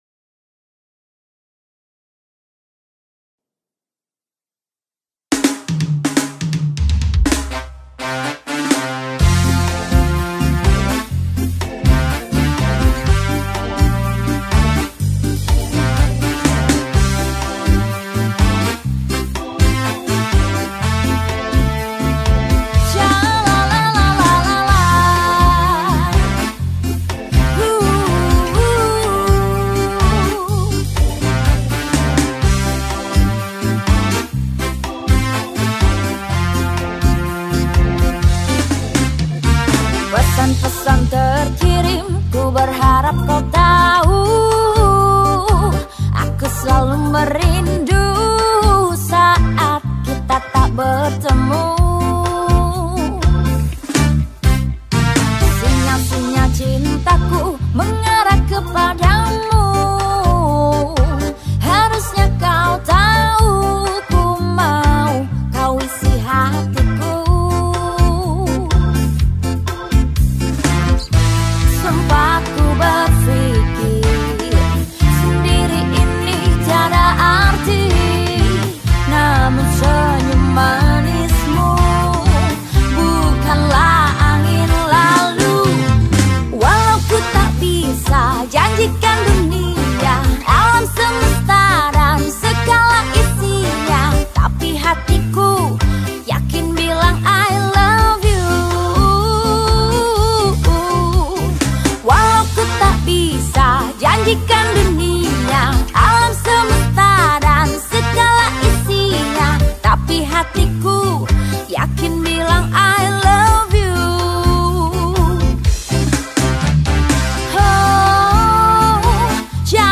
Lagu Hitz 2019 , lagu reggae